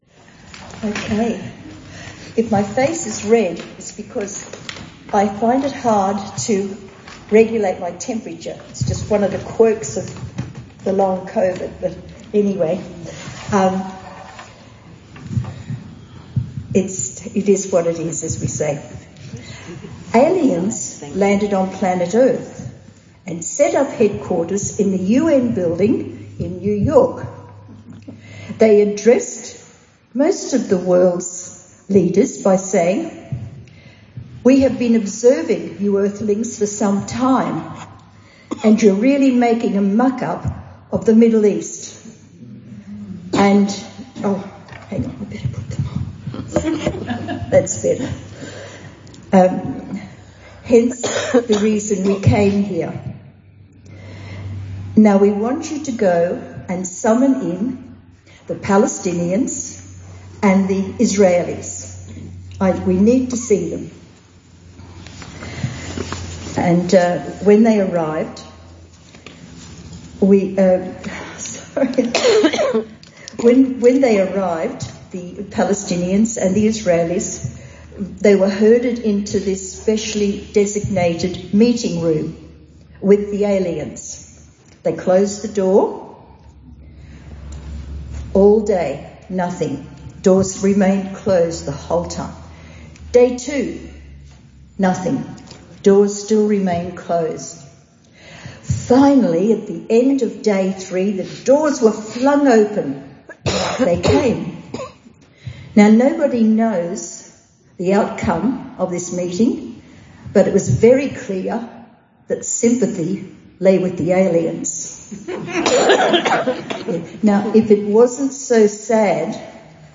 Christmas Gospel Music Presentation